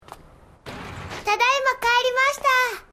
Untuk mendengar versi lengkapnya, kamu bisa download potongan dari anime Clannad episode 19 saat Furukawa Nagisa mengucapkannya:
tadaima_kaerimashita.mp3